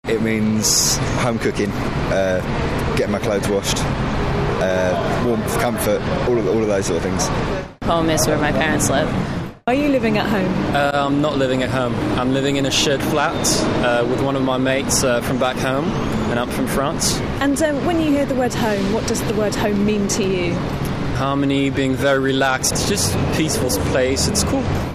Every week we ask you a different question. Hear what people in London say, then join the conversation!